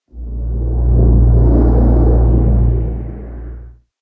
sounds / ambient / cave / cave9.ogg
cave9.ogg